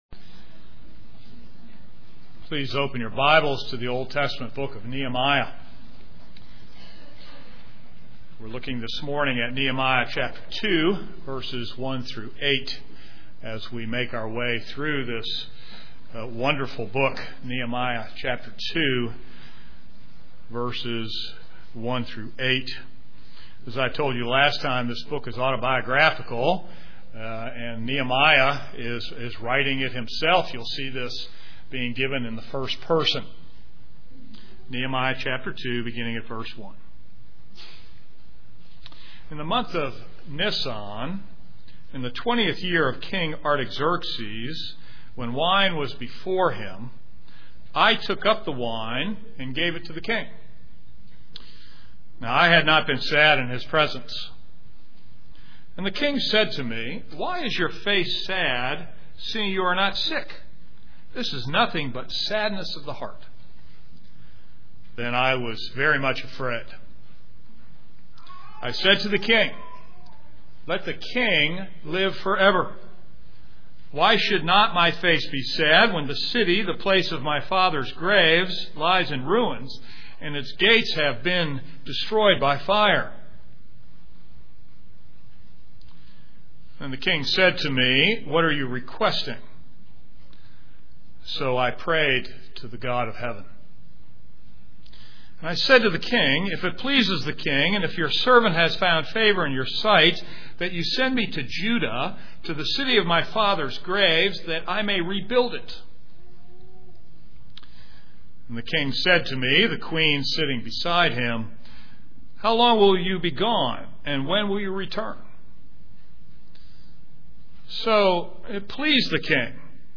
This is a sermon on Nehemiah 2:1-8.